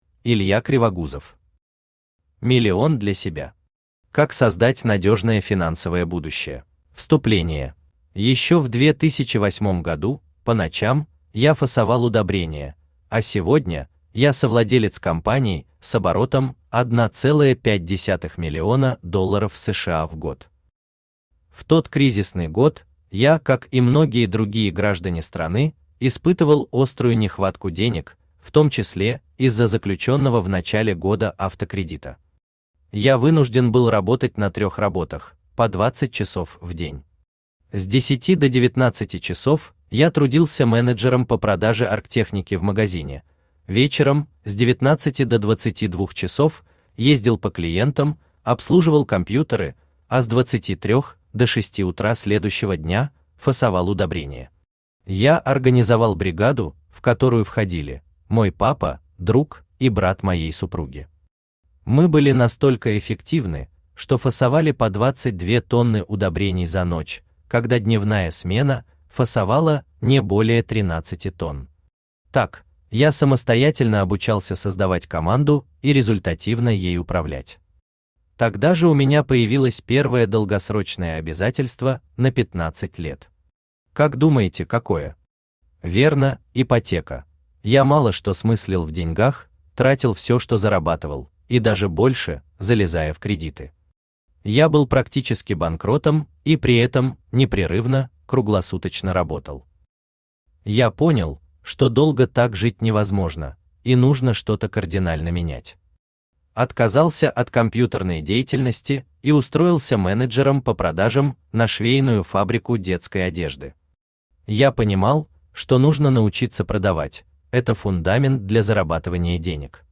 Аудиокнига Миллион для себя. Как создать надежное финансовое будущее | Библиотека аудиокниг
Как создать надежное финансовое будущее Автор Илья Александрович Кривогузов Читает аудиокнигу Авточтец ЛитРес.